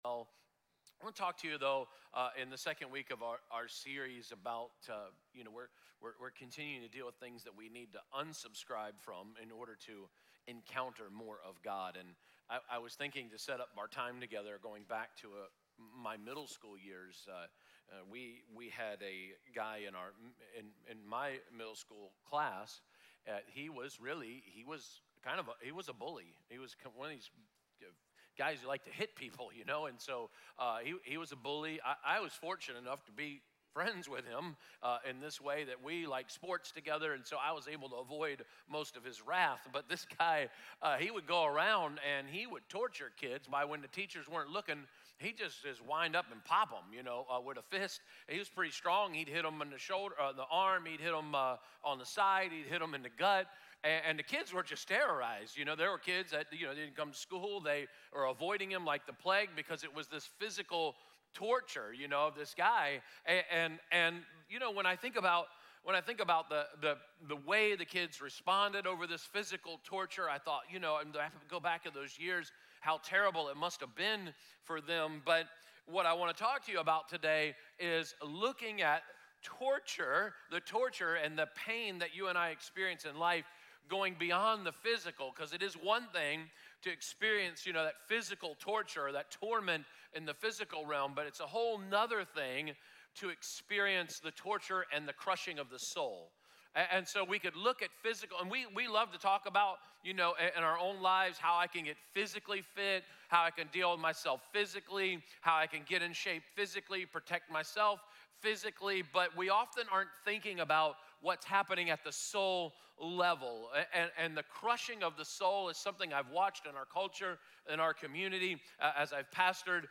Listen to Message